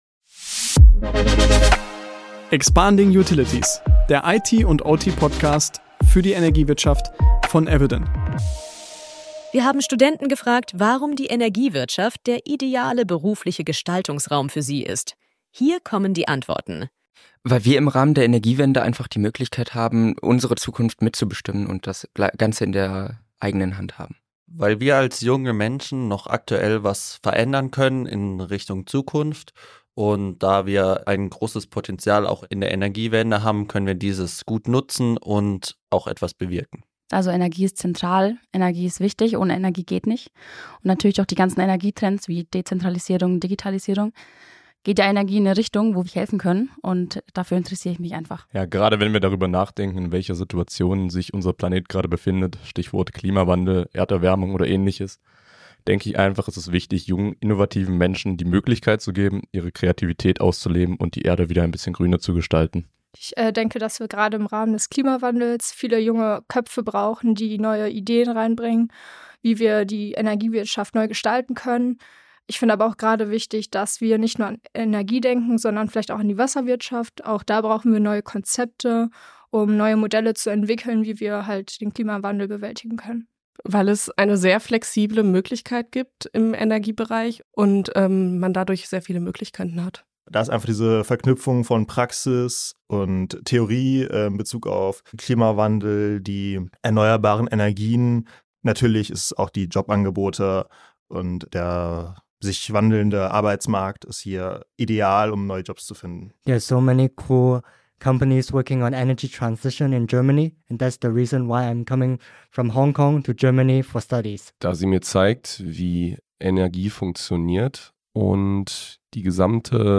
Wir haben Studenten gefragt, warum die Energiewirtschaft der ideale